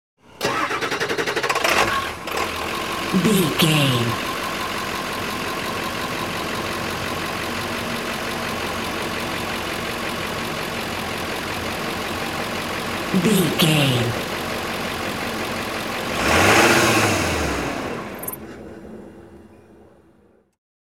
Ambulance Ext Diesel Engine Turn on off
Sound Effects
urban
emergency